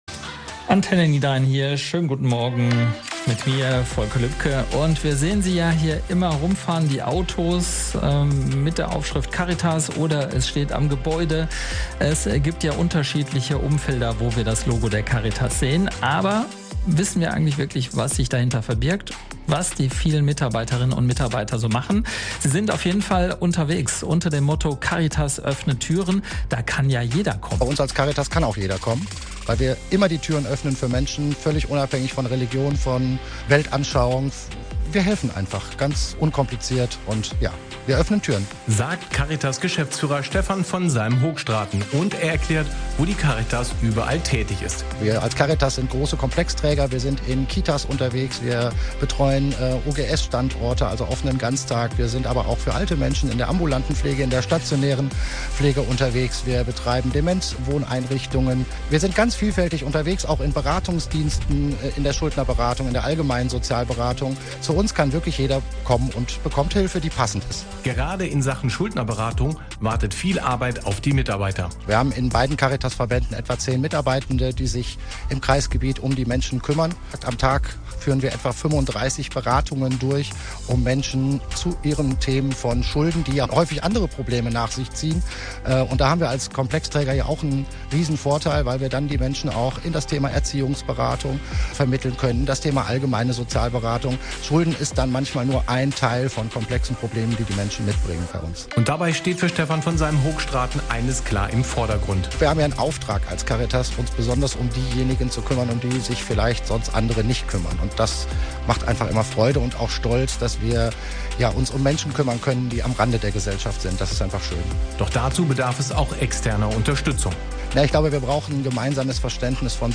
Die Caritas im Kreis Kleve (Geldern-Kevelaer und Kleve) hatte ihren Jahresempfang in der bofrost*HALLE in Straelen.
caritas-jahresempfang-2025-in-straelen.mp3